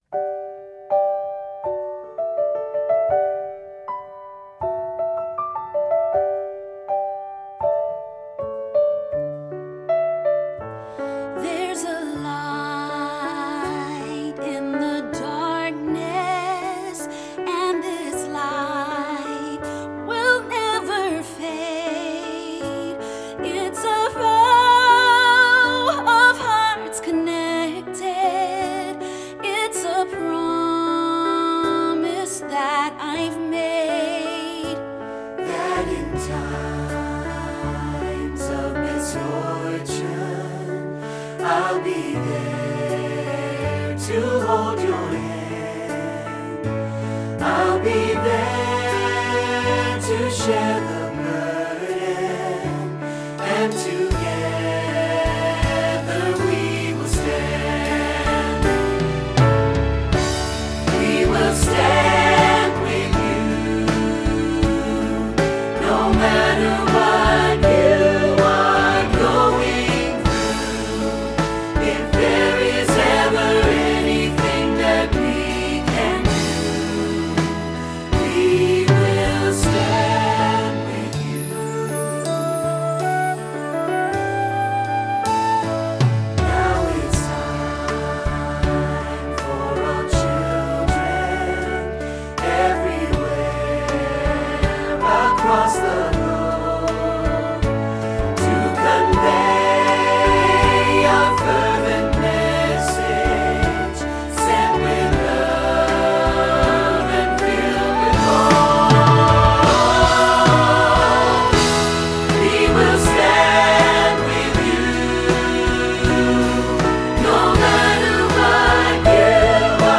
pop choral
SATB recording